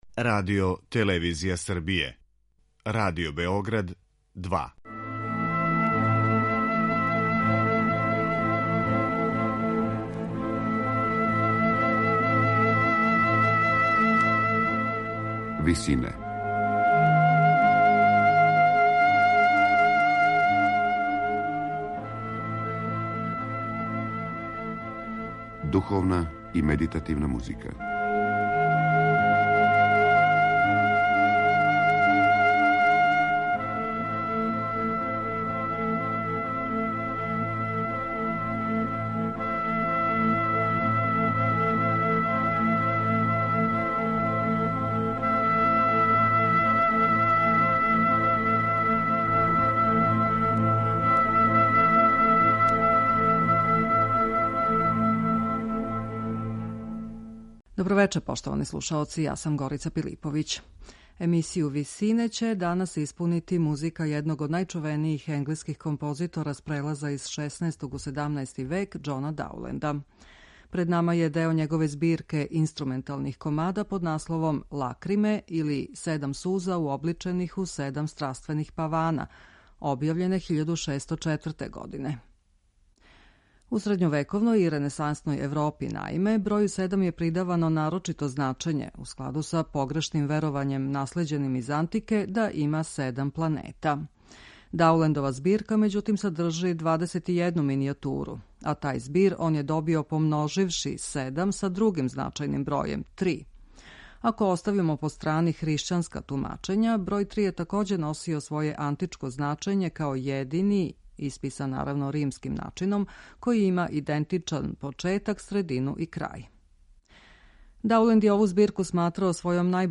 Биће то део његове збирке инструменталних комада под називом Лакриме или Седам суза уобличених у седам страствених павана.
Она је добар пример како његовог мајсторства, тако и доминирајућег меланхоличног осећања, карактеристичног за ту епоху уопште.